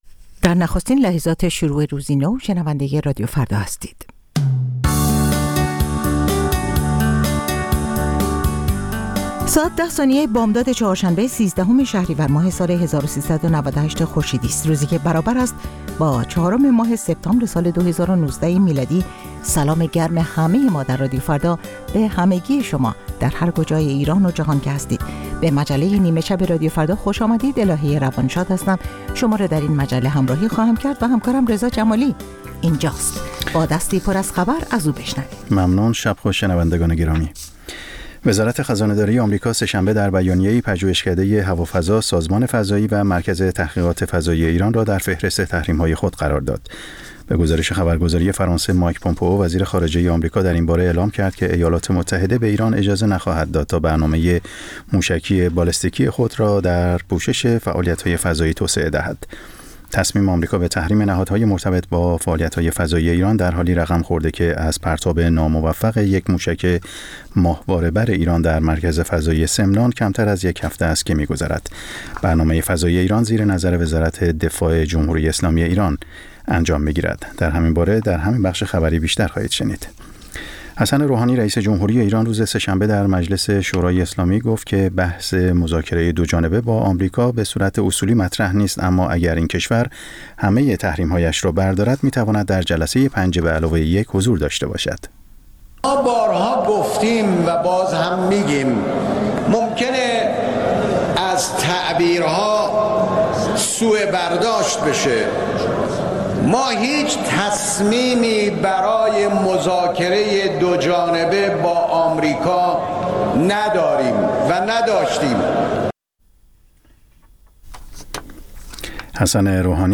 همچون هر روز، مجله نیمه‌شب رادیو فردا، تازه‌ترین خبرها و مهم‌ترین گزارش‌ها را به گوش شما می‌رساند.